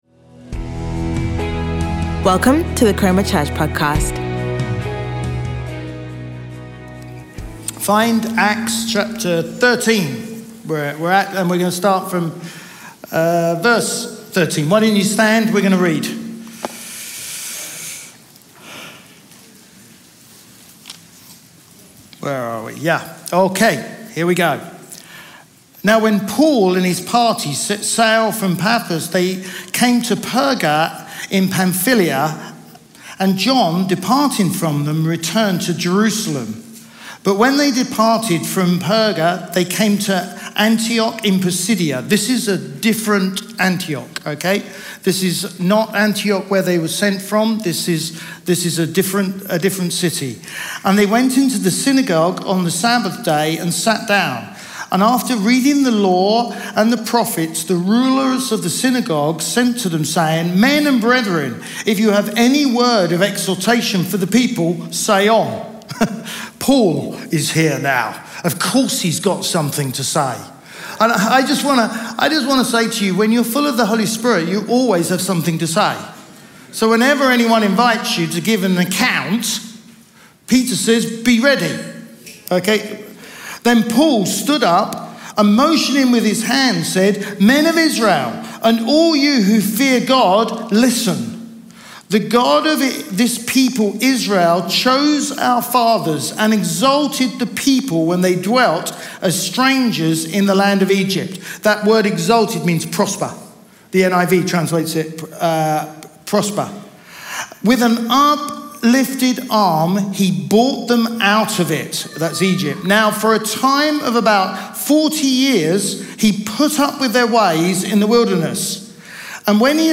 Chroma Church - Sunday Sermon Become Part of God's Story